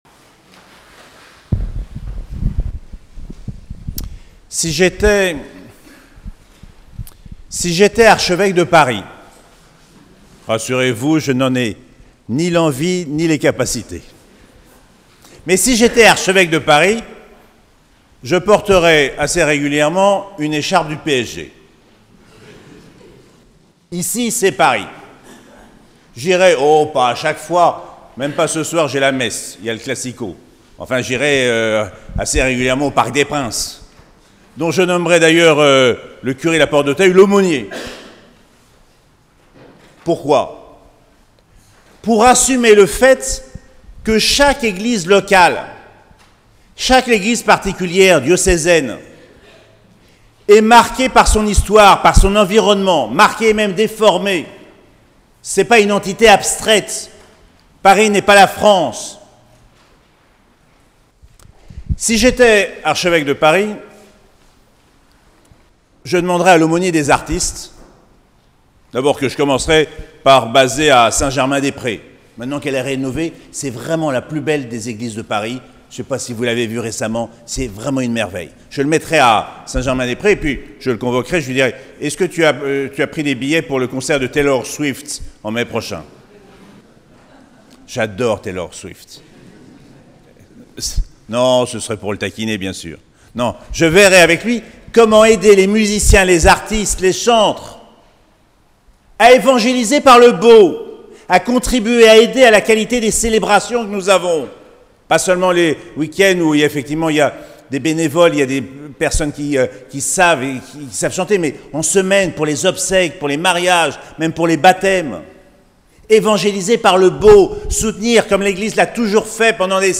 25ème dimanche du temps ordinaire - 24 septembre 2023